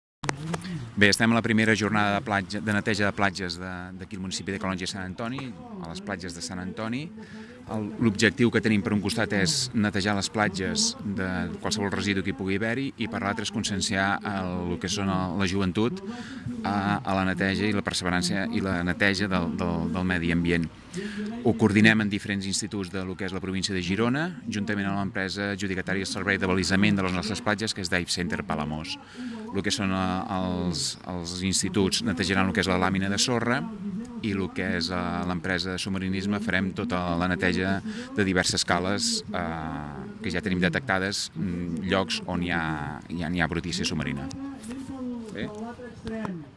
Així ho ha explicat Jordi Caner, regidor de platges.